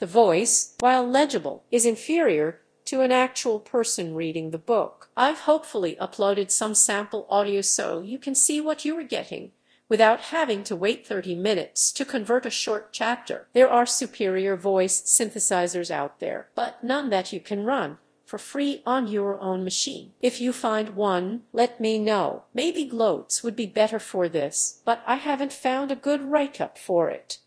Using tacotron2 -> waveglow to convert .epub into audiobooks.
The voice, while legible, is inferior to an actual person reading the book.
Convert .epub into audiobooks via AI Text-to-Speech
That being said, listening at this speed is quite fatiguing, as the AI rarely makes pauses, and occasionally pronounces things weird.